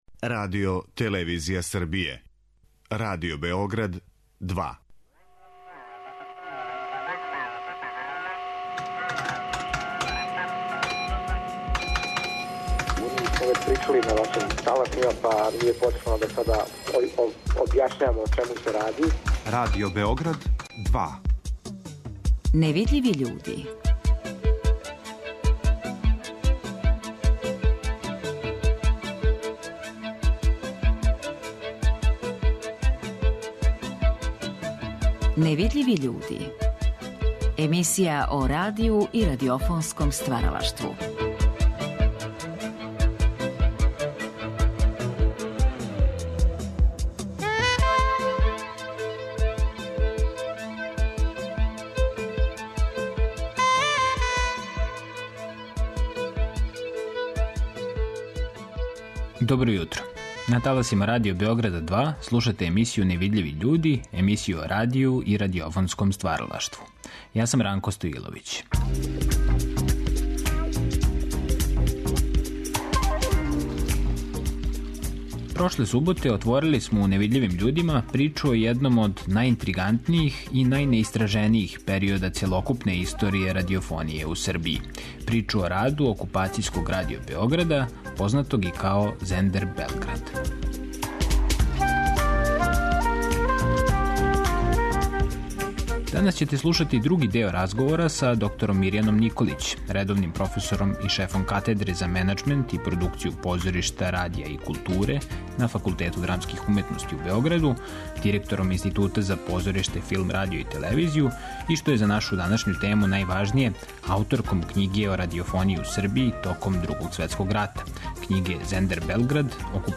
У наставку разговора биће речи о едукативним програмима Окупацијског Радио Београда, о продукцијски најзахтевнијим програмским форматима, као и о раду осталих радио-станица током Другог светског рата. Из ризнице нашег Тонског архива чућете накнадна сећања неких од директних актера ових догађаја.